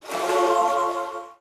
ui_interface_222.wav